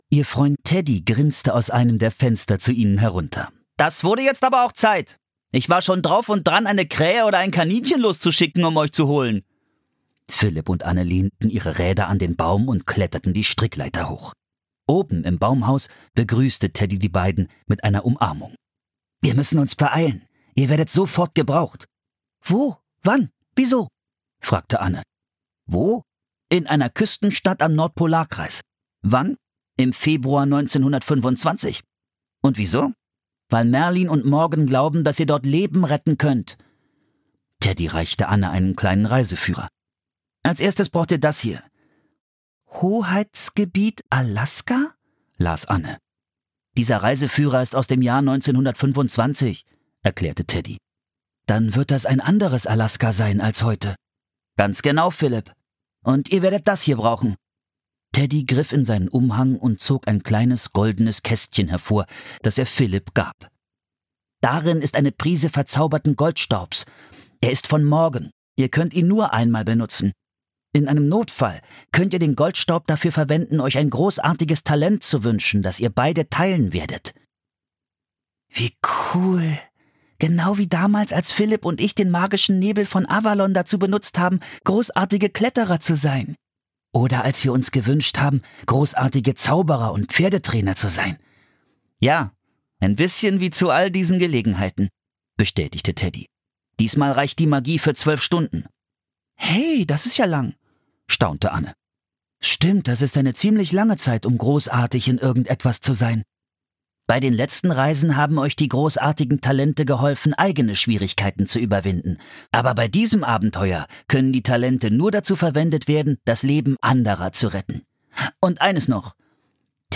Wettlauf der Schlittenhunde (Das magische Baumhaus 52) - Mary Pope Osborne - Hörbuch